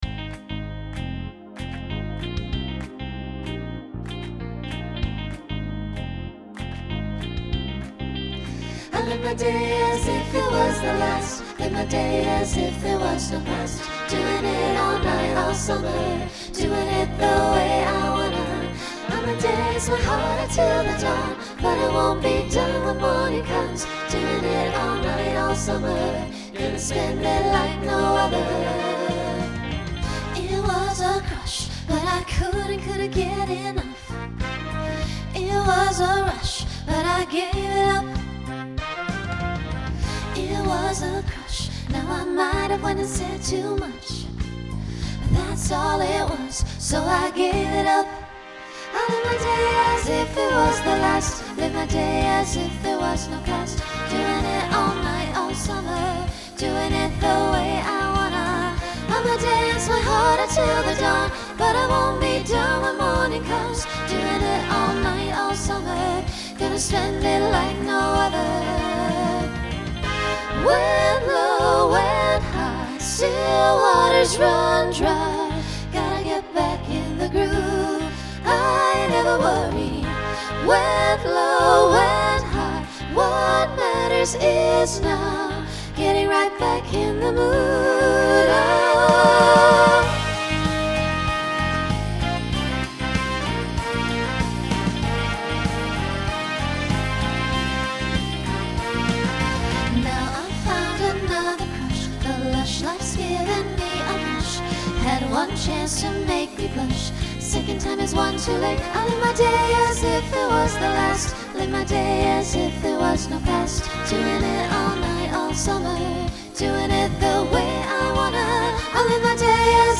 SATB/SSA/TTB/SATB
Genre Pop/Dance
Transition Voicing Mixed